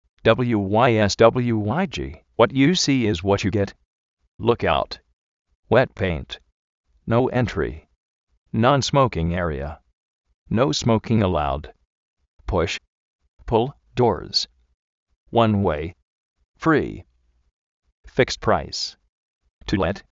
lukáut
uét péint
nóu éntri
uán uéi
fíkst práis